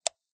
click_plastic_single.ogg